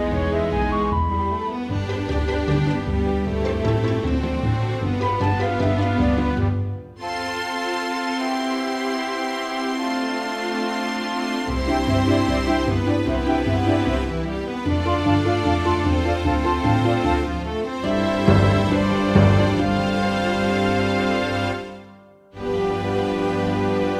no Backing Vocals Musicals 3:41 Buy £1.50